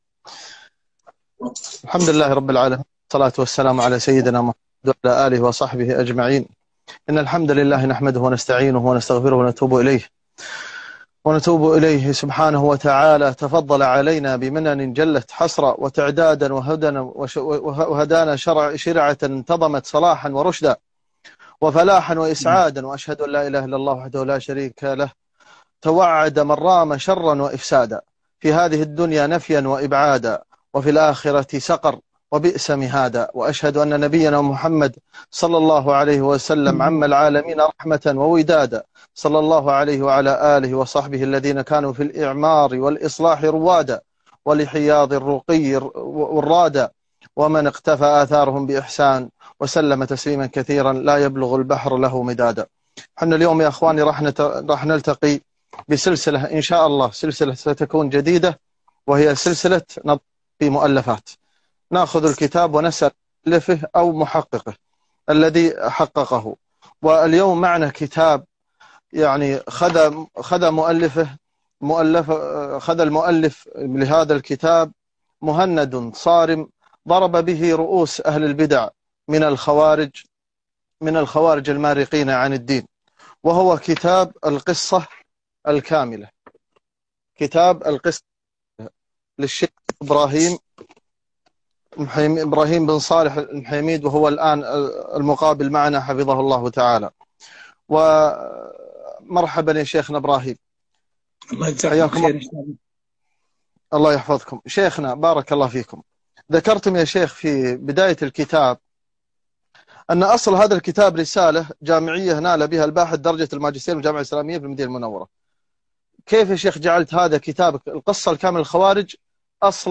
لقاء حول كتاب القصة الكاملة لخوارج عصرنا مع مؤلفه - الجزء الأول